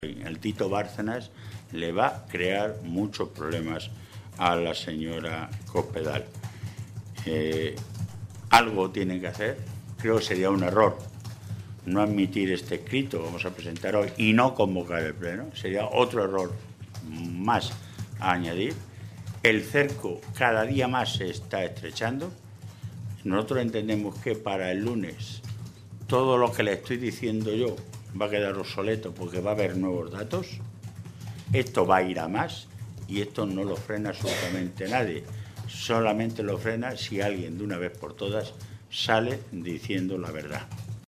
Vaquero hacía este anuncio esta mañana, en Toledo, en una comparecencia ante los medios de comunicación en la que advertía que sería “un error”, que la mayoría del PP en la Mesa de la Cámara se negara a calificar y tramitar la propuesta socialista.
Cortes de audio de la rueda de prensa